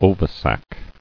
[o·vi·sac]